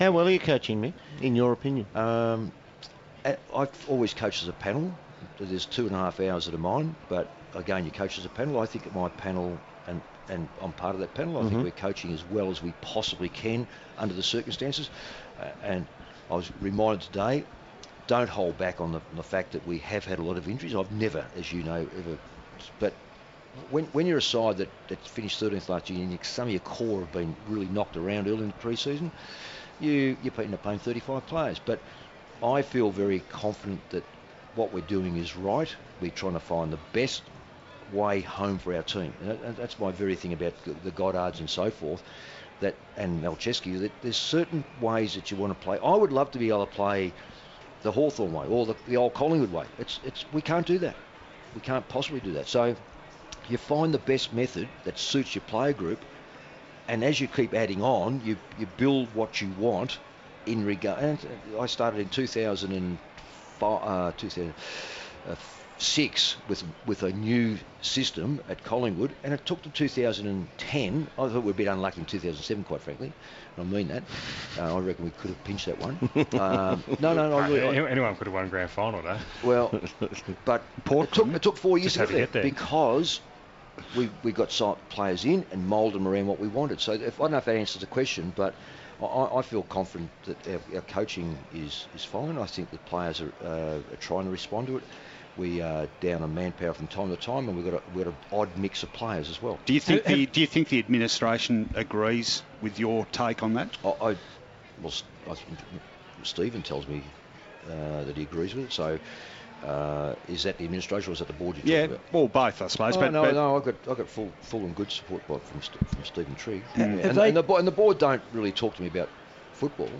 Carlton coach Mick Malthouse chats on The Coaches Box about how he is coaching and is coaching future.